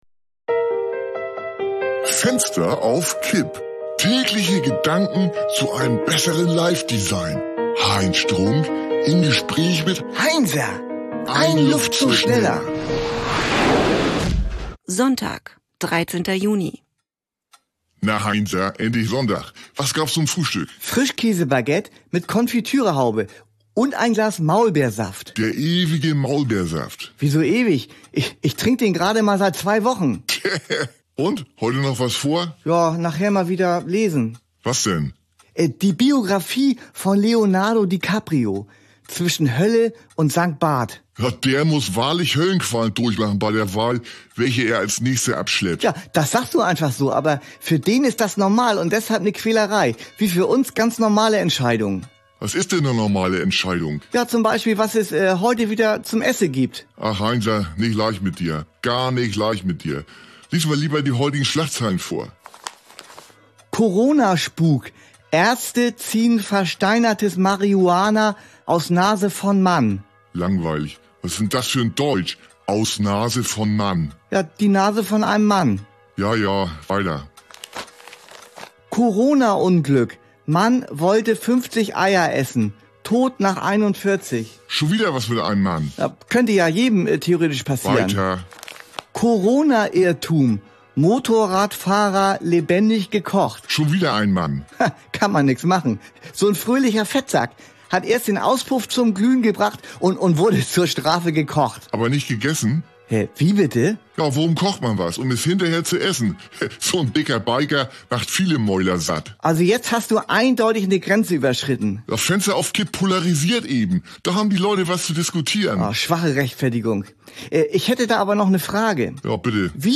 eine Audio-Sitcom von Studio Bummens